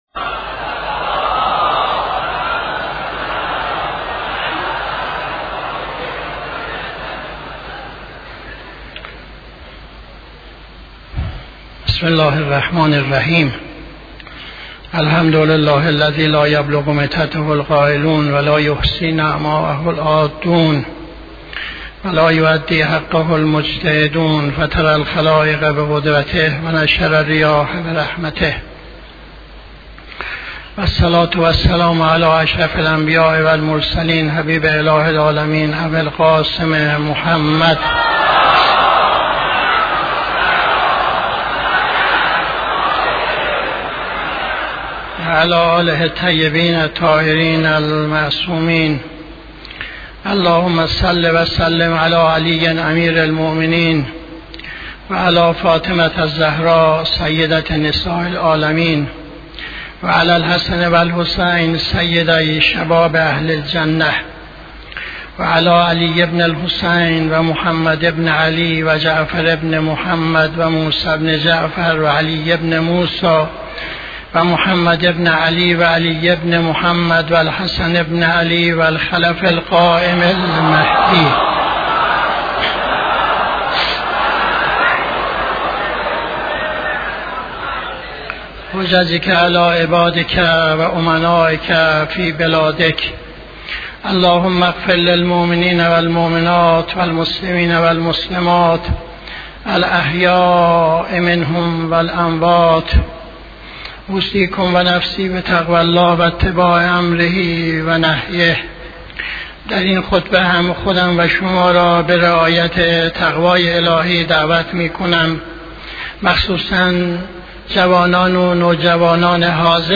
خطبه دوم نماز جمعه 30-06-80